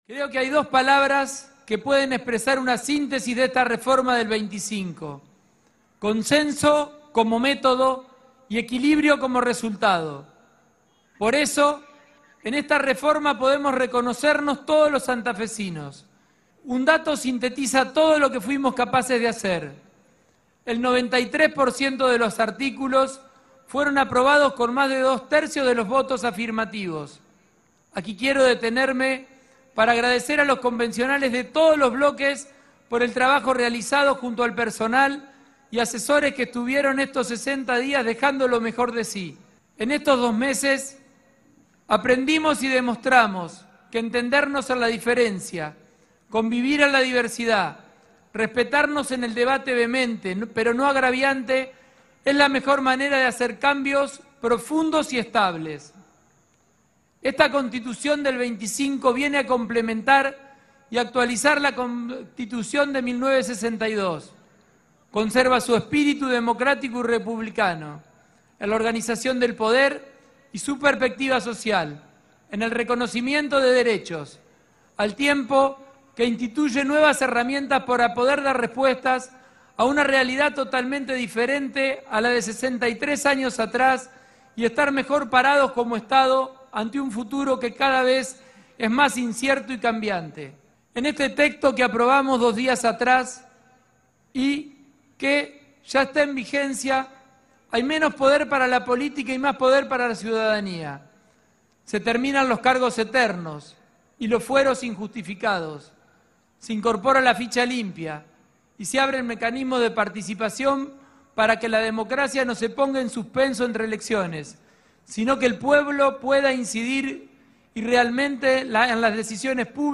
El gobernador Maximiliano Pullaro participó este viernes en el acto de cierre de la Convención Reformadora de la Constitución de la Provincia de Santa Fe, realizado en la explanada de la Legislatura.